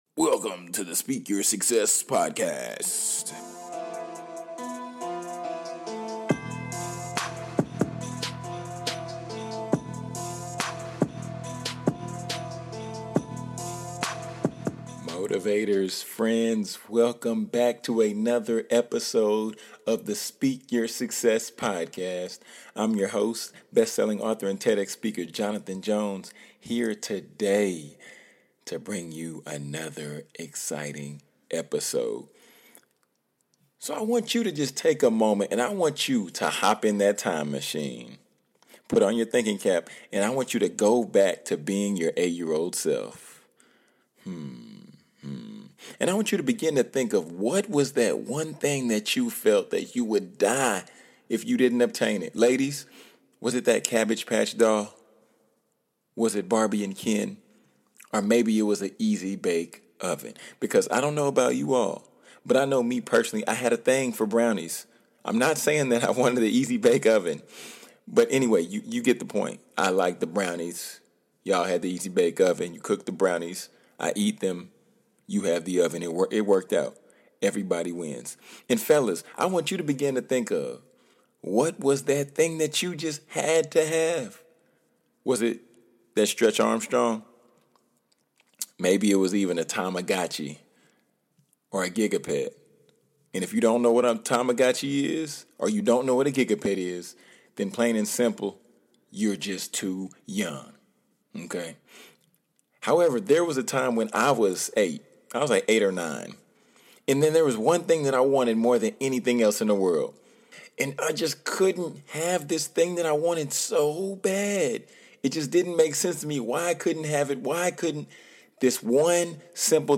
"FUEGO" DEEP HARD TRAP BEAT